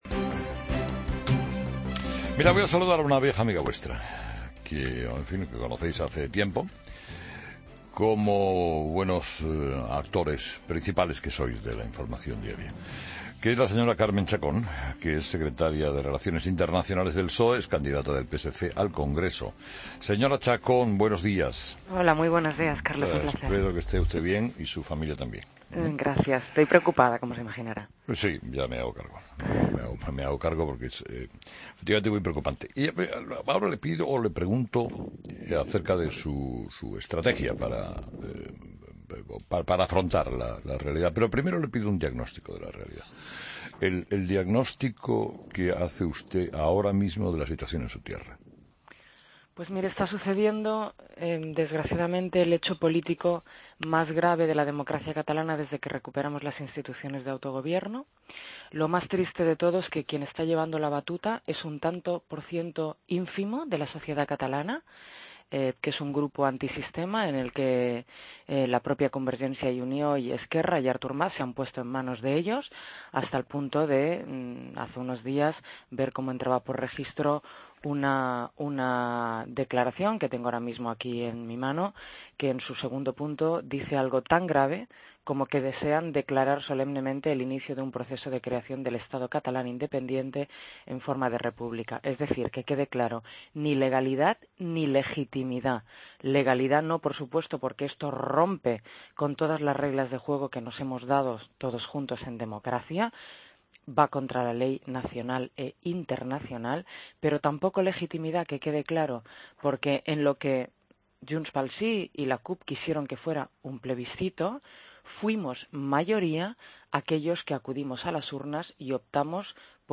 Escucha la entrevista a Carme Chacón en 'Herrera en COPE'